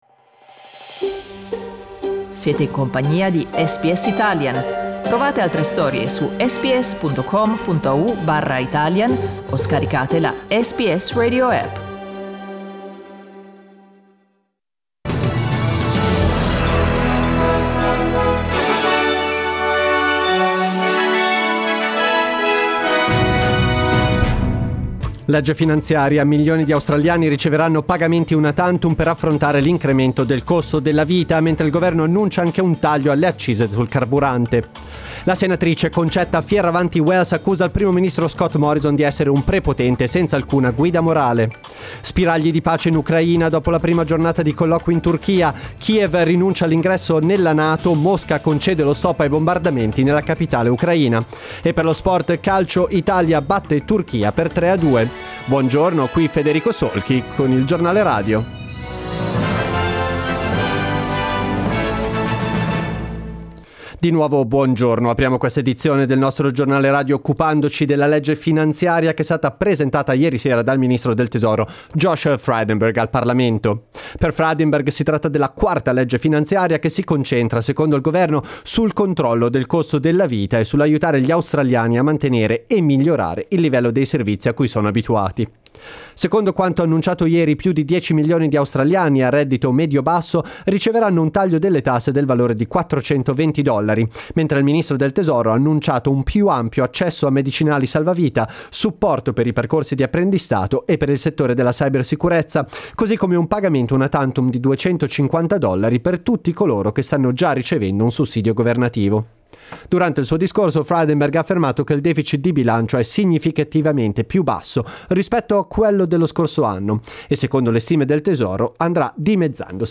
Giornale radio mercoledì 30 marzo 2022
Il notiziario di SBS in italiano.